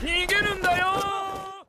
tao gerundayo Meme Sound Effect
Category: Anime Soundboard